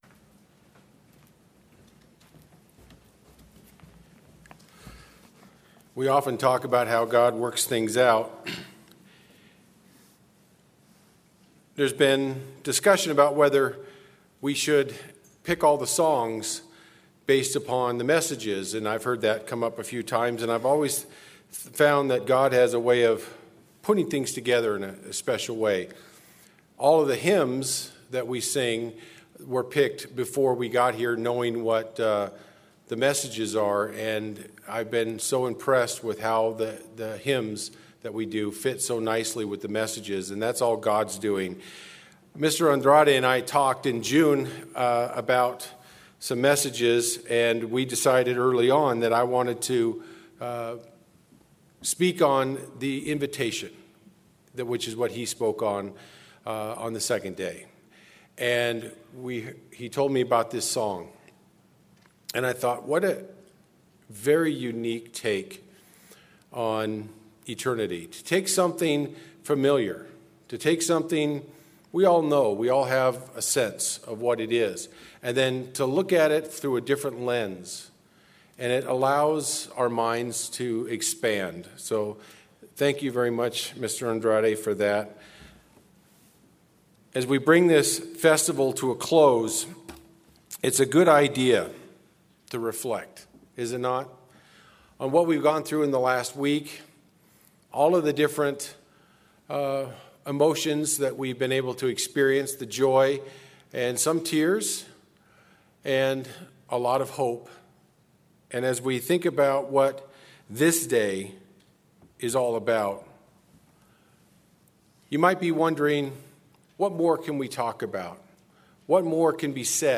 This sermon was given at the Bend-Redmond, Oregon 2022 Feast site.